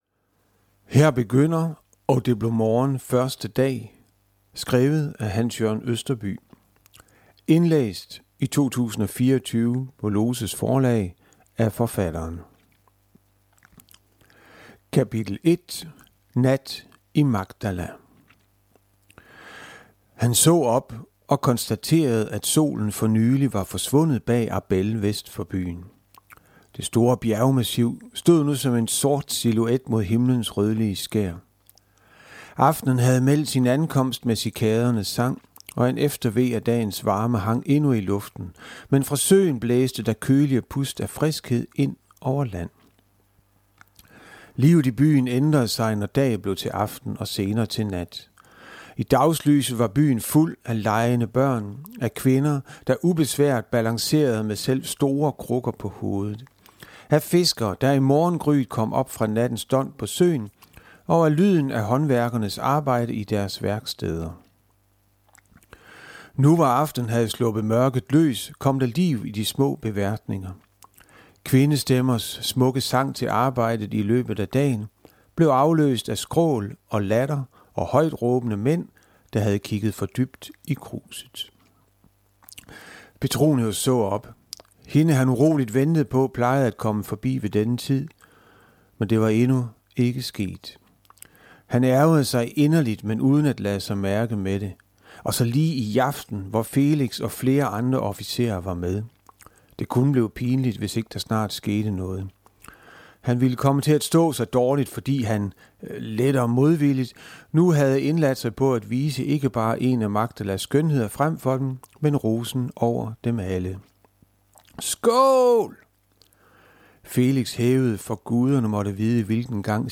Og det blev morgen, første dag - MP3 lydbog